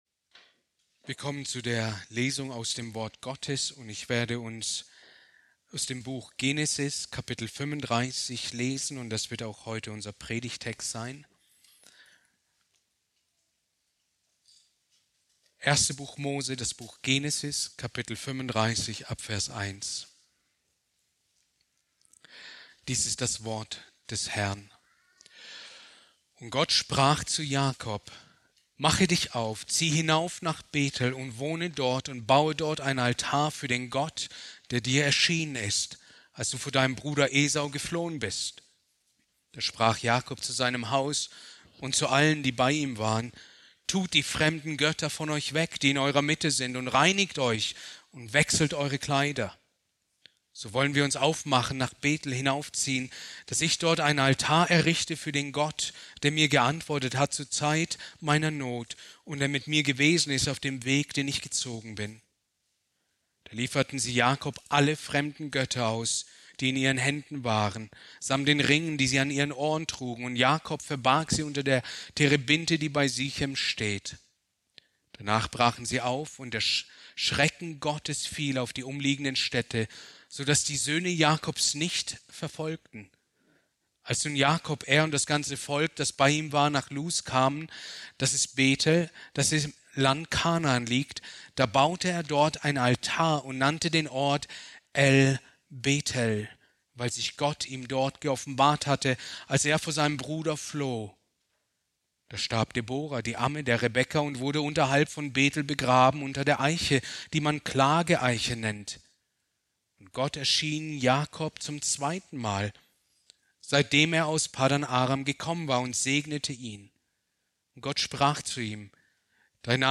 Predigt aus der Serie: "Genesis"